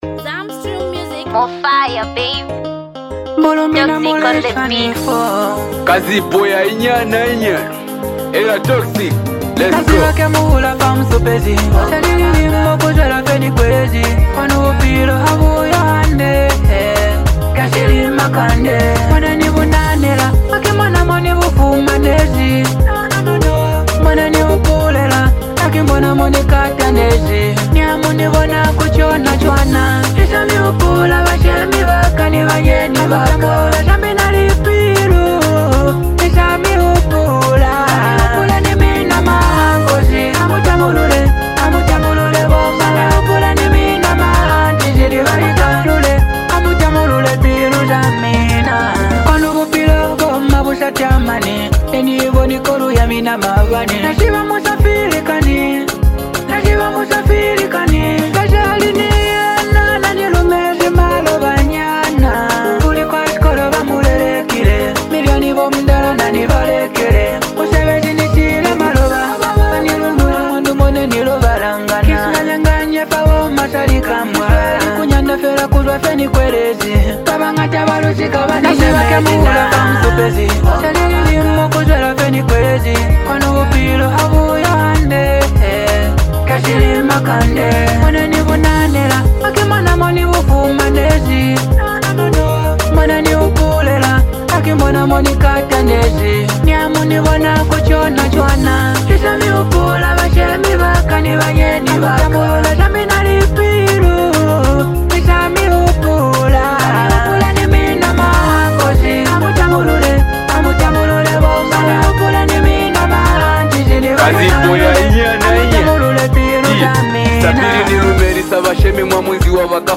This EP blends emotion, culture, and smooth melodies